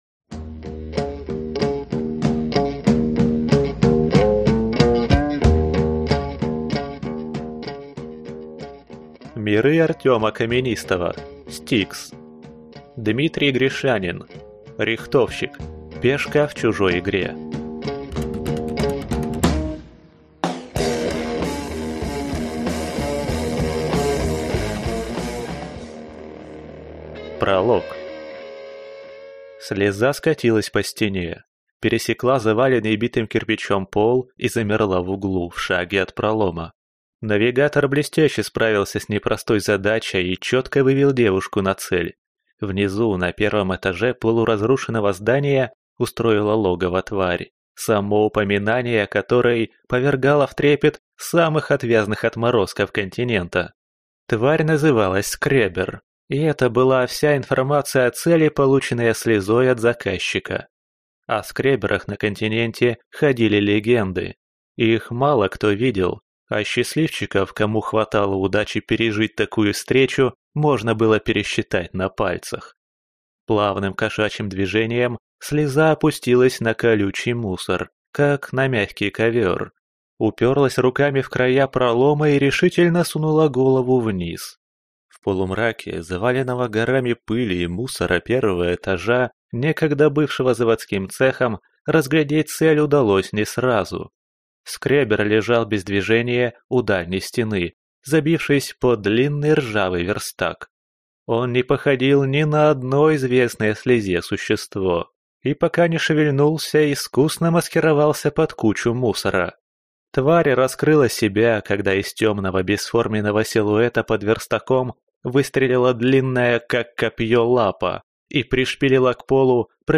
Аудиокнига S-T-I-K-S. Рихтовщик. Пешка в чужой игре | Библиотека аудиокниг